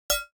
4_plink_2.ogg